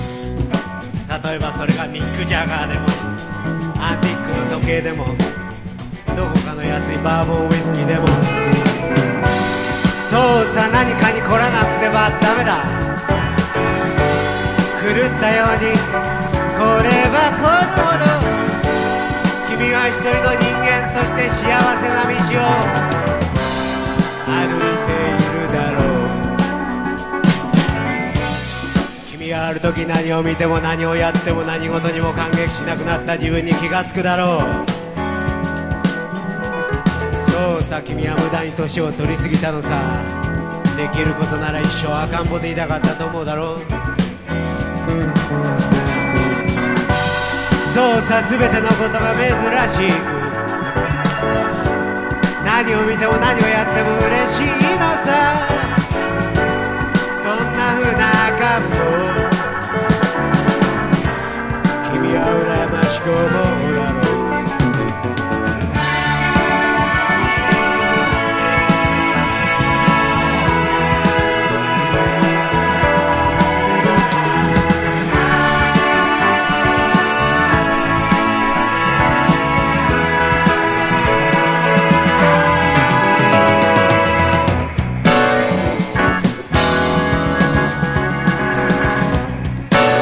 ライブ音源盤
60-80’S ROCK